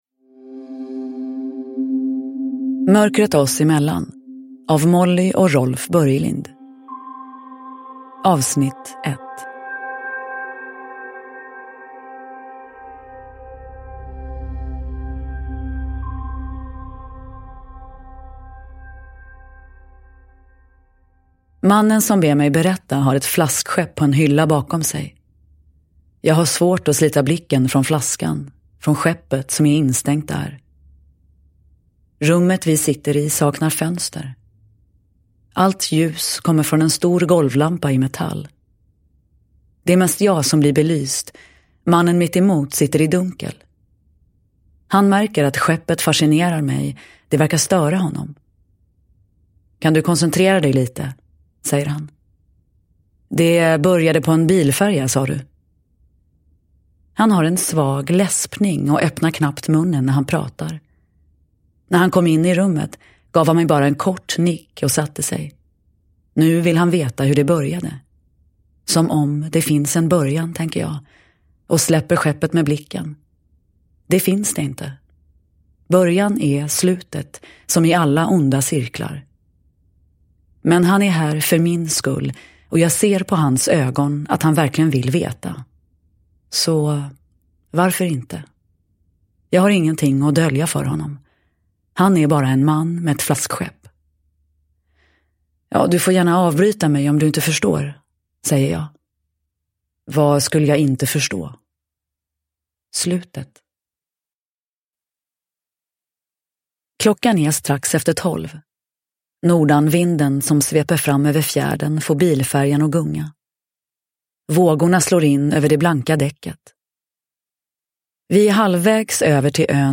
Uppläsare: Nina Zanjani